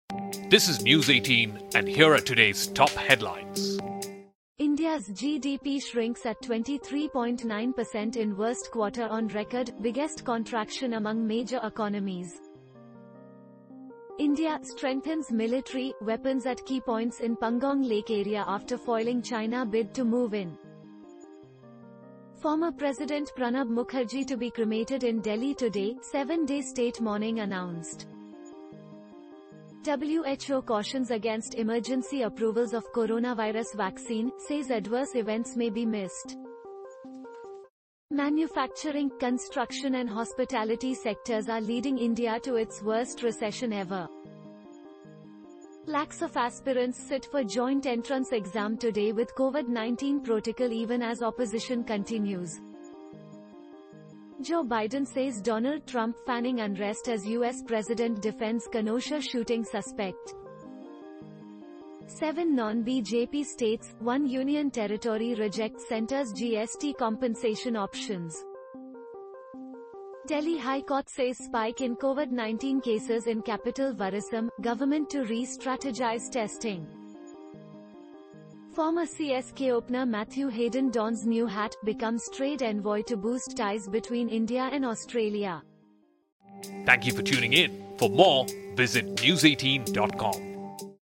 Catch up with the top headlines of the day with our Audio Bulletin, your daily news fix in under 2 minutes.